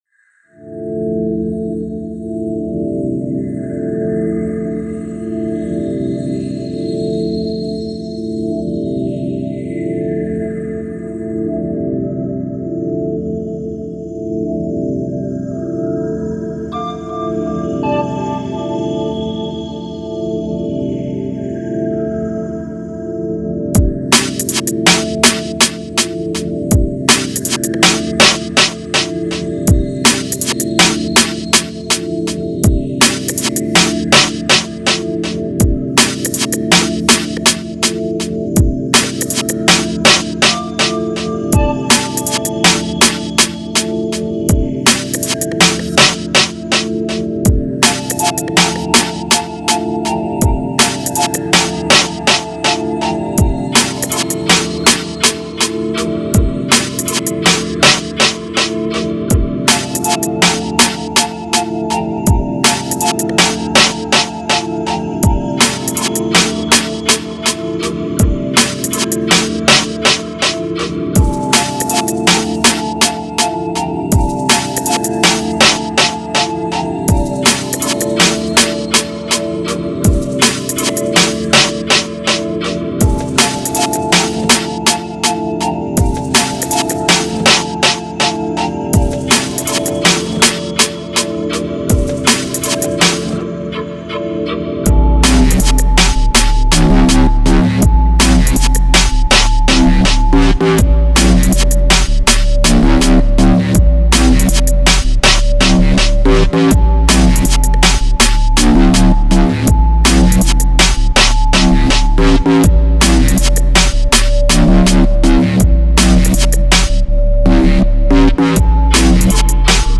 DEEP DNB